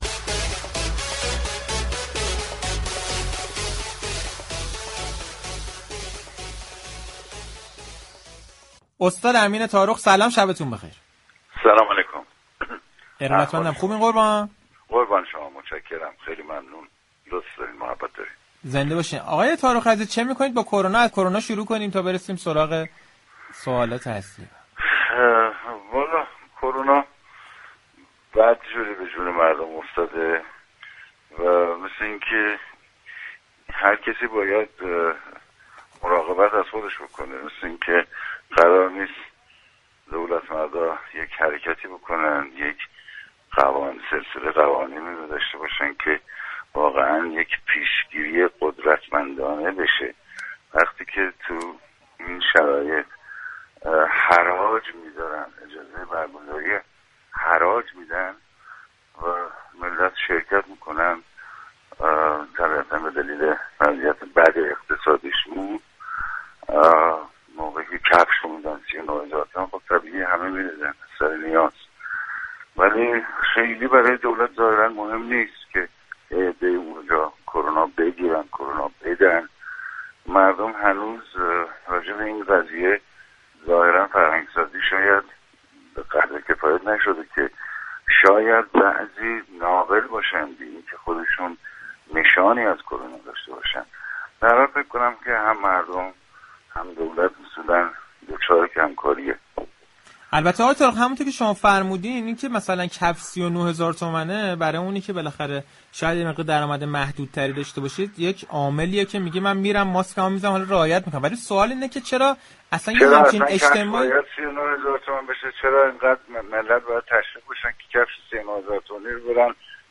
امین تارخ بازیگر توانمند و پیشكسوت كشورمان درباره سریال آقازاده گفت: برخی معضلات اجتماعی كه توسط برخی آقازاده ها وغیر آقازاده ها به وجود آمده در این سریال به تصویر كشیده می شود.
به گزارش پایگاه اطلاع رسانی رادیو تهران، امین تارخ هنرمند محبوب كشورمان در گفتگو با برنامه پشت صحنه 24 آبانماه درباره شرایط بحرانی شیوع كرونا در كشور گفت: ظاهرا قرار است هر كسی به طور فردی مراقب خود باشد.